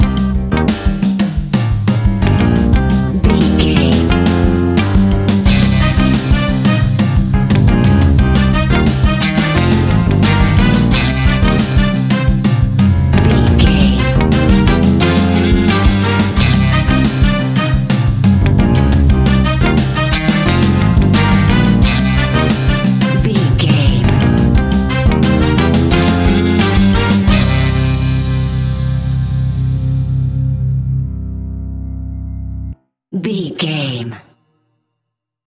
Aeolian/Minor
flamenco
latin
salsa
uptempo
drums
bass guitar
percussion
brass
saxophone
trumpet
fender rhodes
clavinet